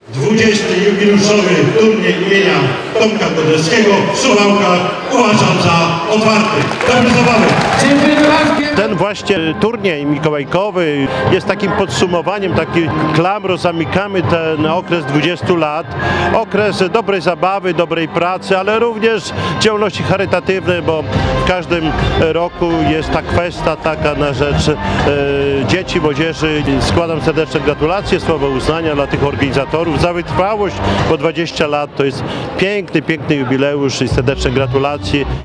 XX jubileuszową edycje turnieju zorganizowano w nowej hali sportowo-widowiskowej Suwałki Arena.
Imprezę otworzył oficjalnie Czesław Renkieiwcz, prezydent Suwałk.